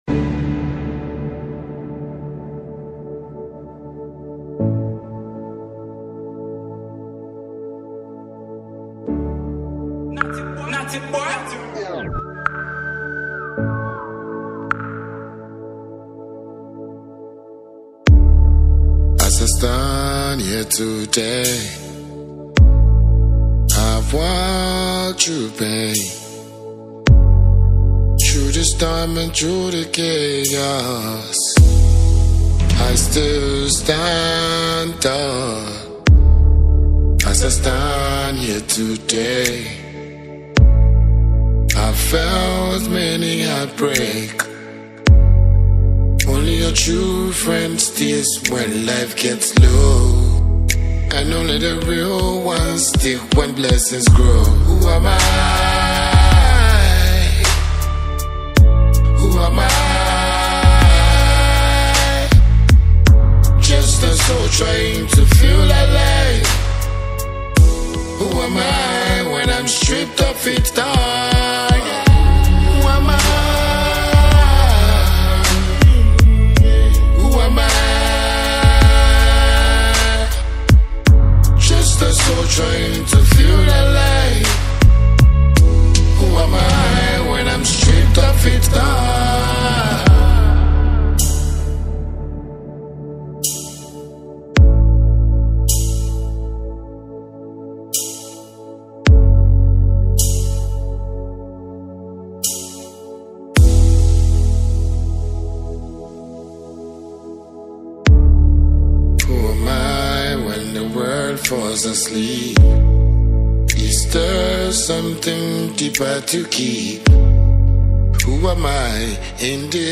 African dancehall king